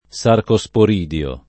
sarcosporidio
vai all'elenco alfabetico delle voci ingrandisci il carattere 100% rimpicciolisci il carattere stampa invia tramite posta elettronica codividi su Facebook sarcosporidio [ S arko S por & d L o ] s. m. (zool.); pl.